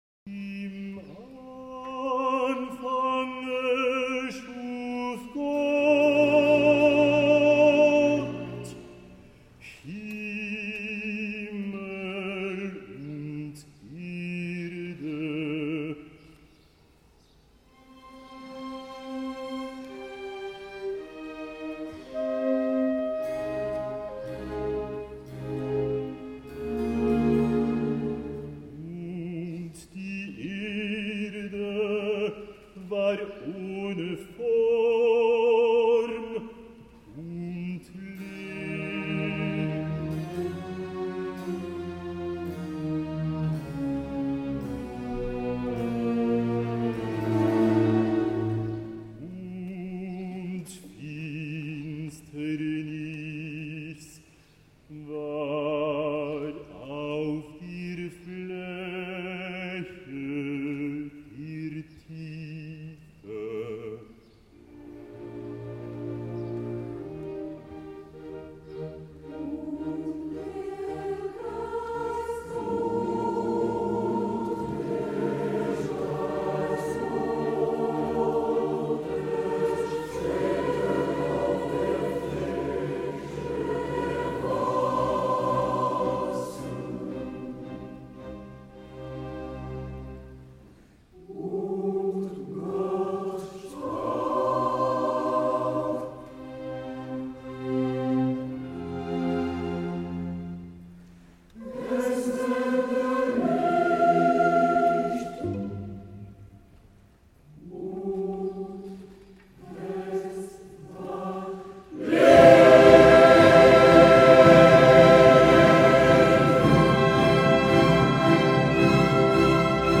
Mp3-lydfil af den del af Haydns Skabelse som blev spillet i begyndelsen af foredraget